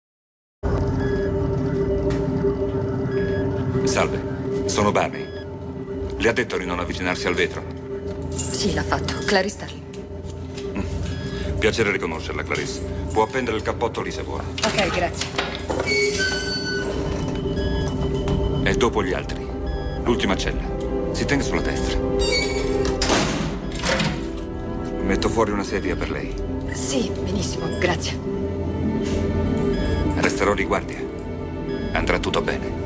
nel film "Il silenzio degli innocenti", in cui doppia Frankie Faison.